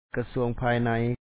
kasúaŋ pháay nay Ministry of Interior